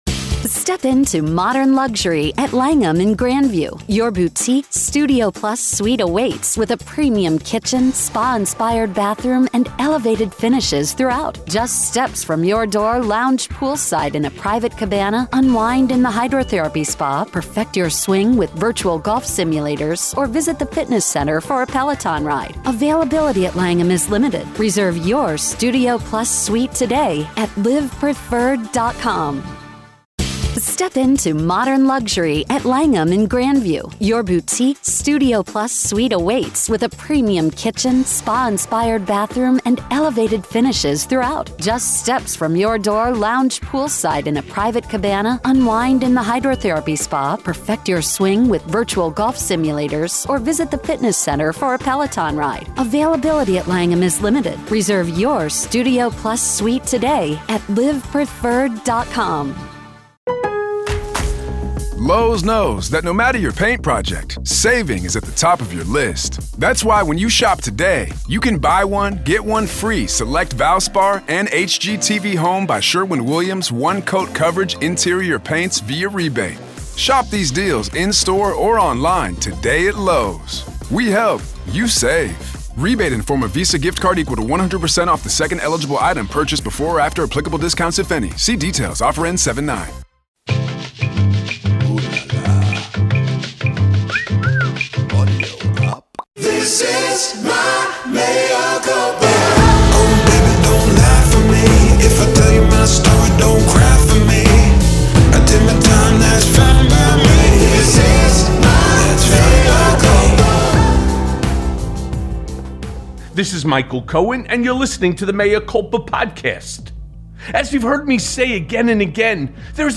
This week Mea Culpa welcomes the legendary actor, comedian, director, and producer Henry Winkler.